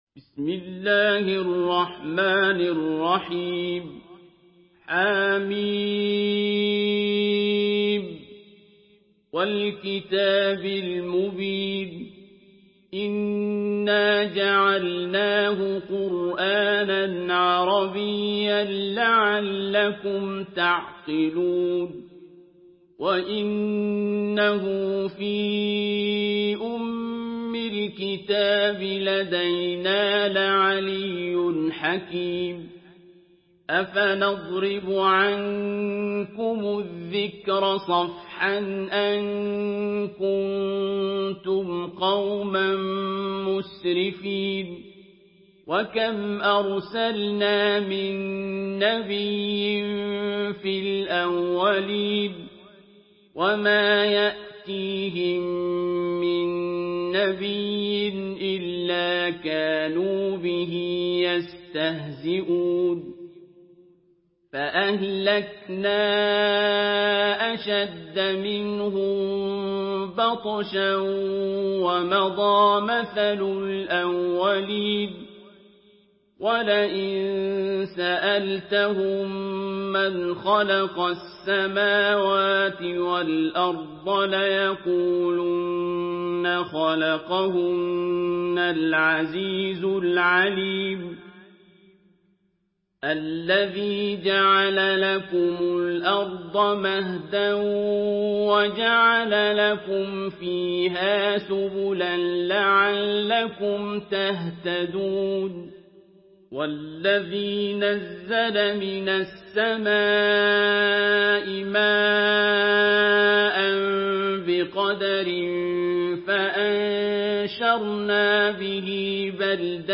Surah Zuhruf MP3 in the Voice of Abdul Basit Abd Alsamad in Hafs Narration
Surah Zuhruf MP3 by Abdul Basit Abd Alsamad in Hafs An Asim narration.
Murattal Hafs An Asim